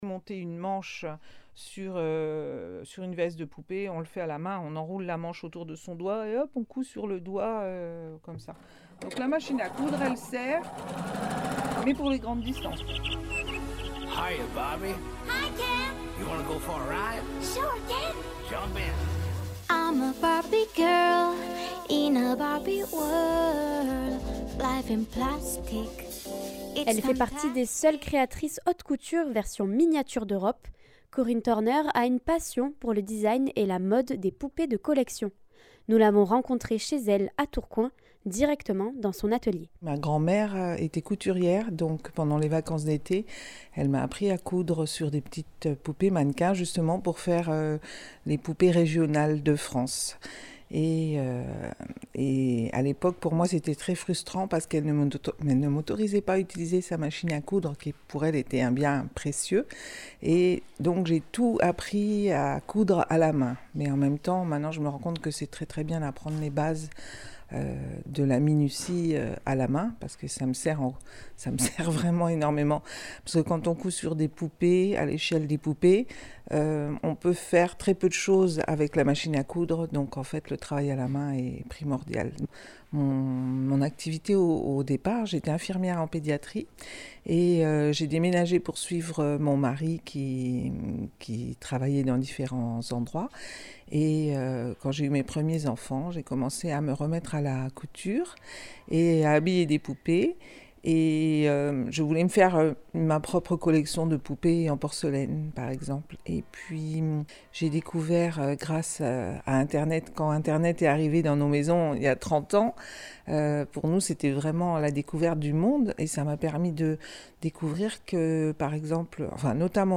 Elle nous à reçu directement, chez elle, dans son lieu de création pour nous parler de son parcours et de ses différents projets.